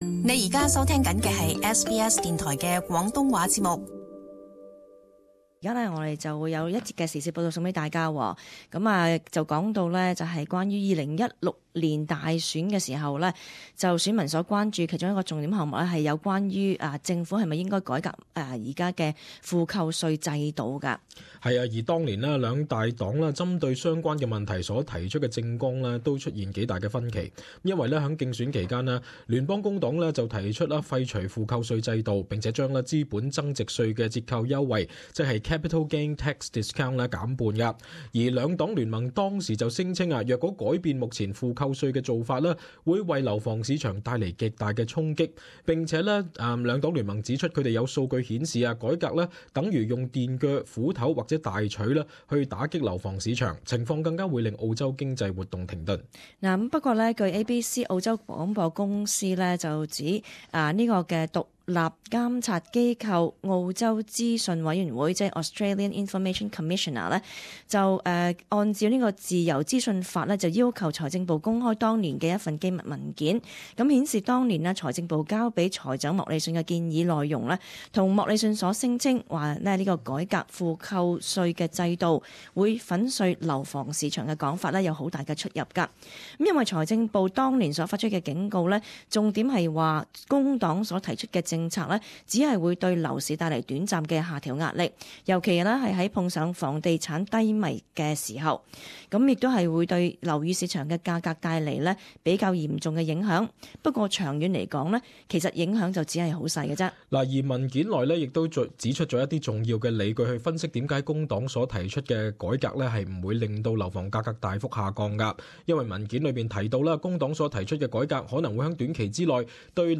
【時事報導】政府文件稱改革負扣稅對屋價影響小